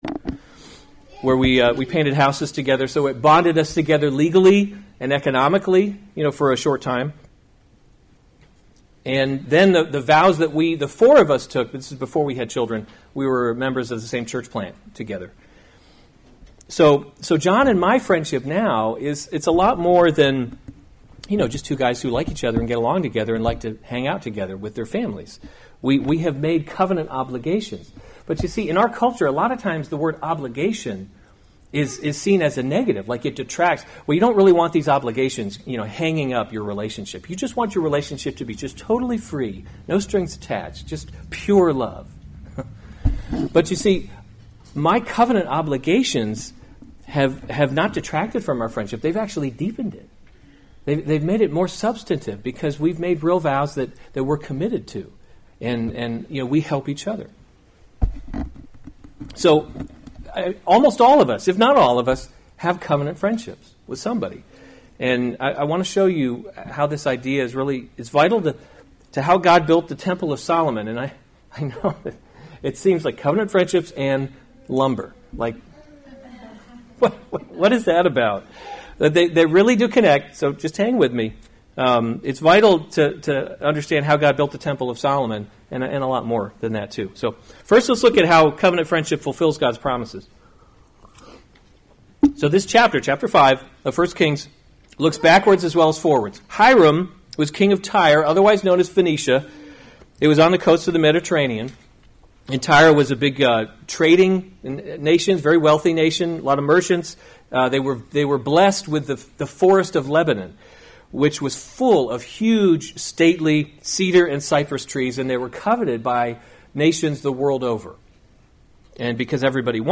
October 6, 2018 1 Kings – Leadership in a Broken World series Weekly Sunday Service Save/Download this sermon 1 Kings 5 Other sermons from 1 Kings Preparations for Building the […]